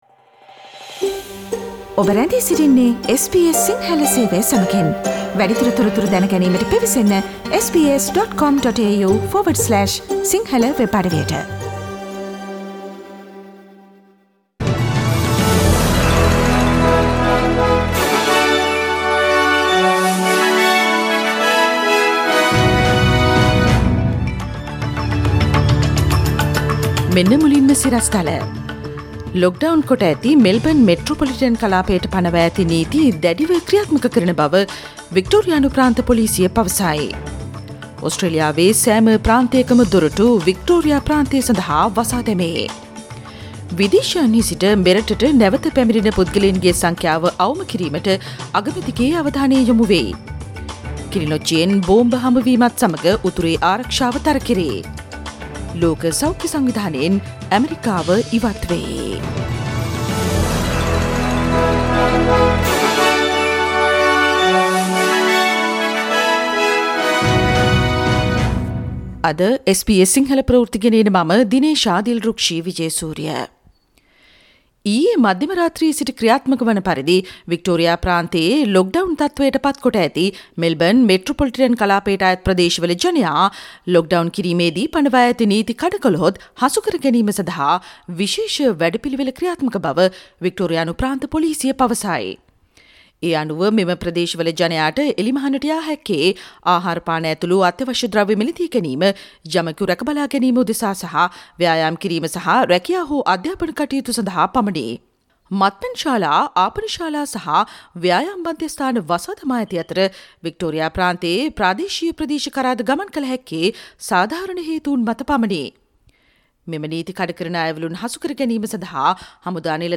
Daily News bulletin of SBS Sinhala Service: Thursday 9 July 2020
Today’s news bulletin of SBS Sinhala radio – Thursday 9 July 2020.